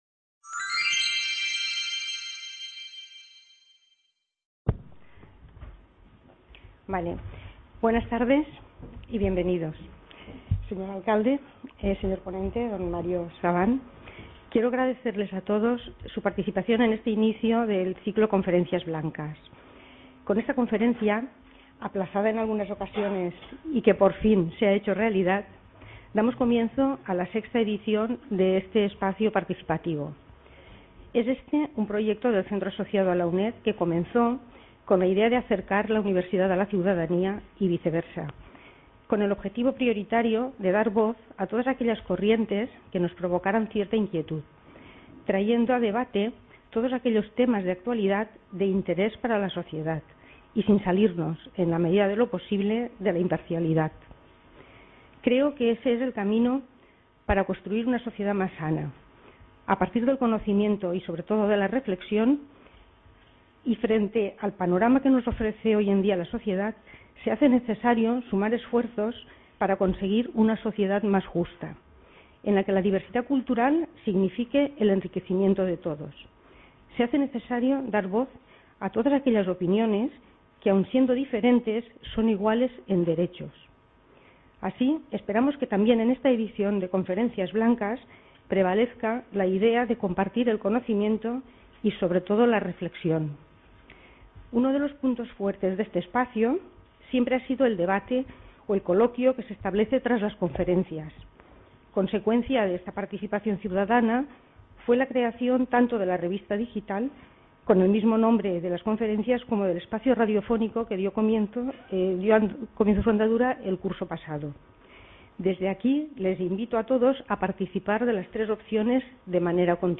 Conferencias Blancas: ¿Hay resentimiento hacia el pueblo judío? Description Conferencia a cargo de